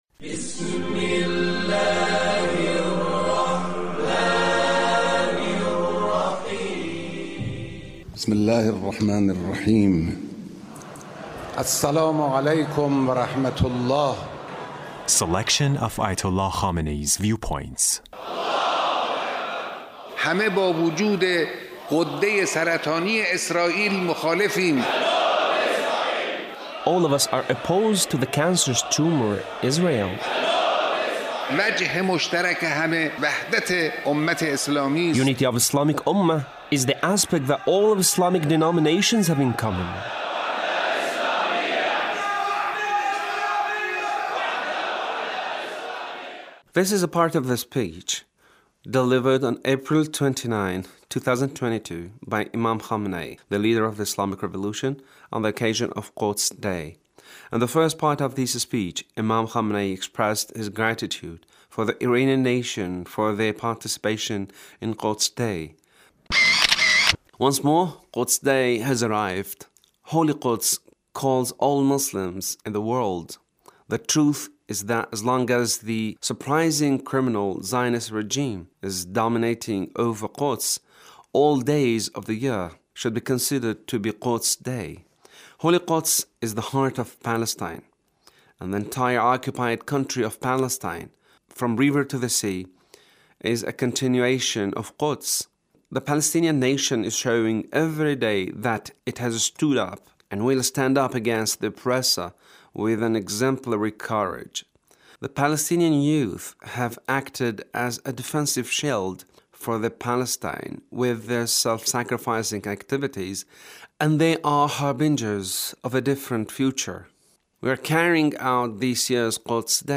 Leader's speech (1401)
The Leader's speech on Quds Day